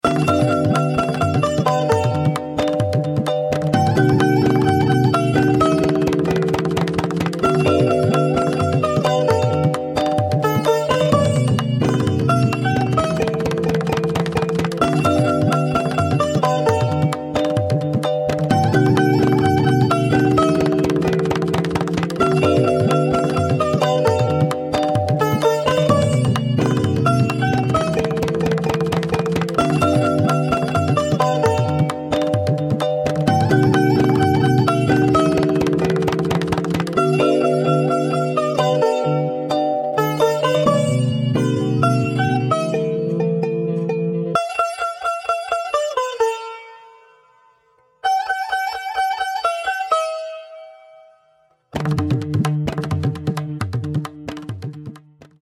• Tamil Kuthu
• Carnatic
• Mridangam